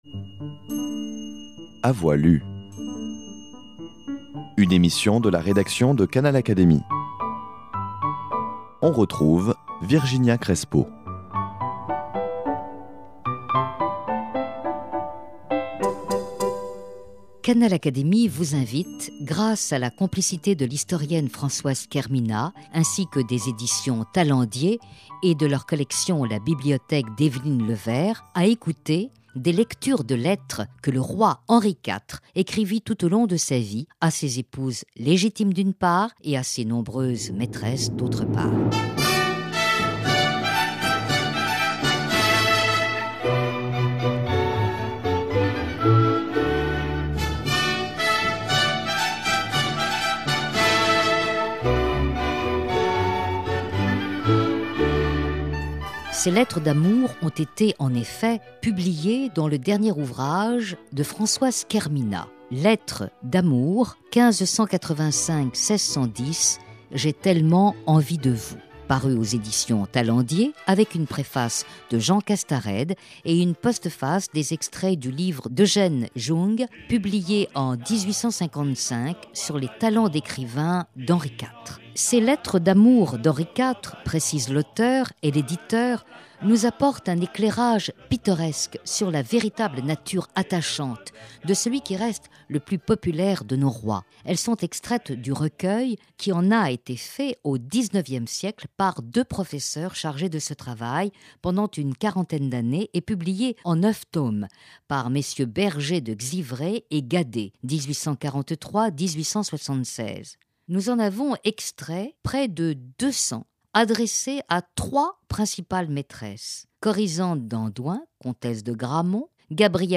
Ecoutez la lecture d’une partie des nombreuses lettres d’amour qu’Henri IV écrivit, tout au long de sa vie, à ses épouses légitimes, Marguerite et Marie, et à ses favorites, du moins à trois d’entre elles : la comtesse de Gramont, Gabrielle d’Estrées et Henriette d’Entragues, lettres qui correspondent à trois périodes fondamentales de son règne.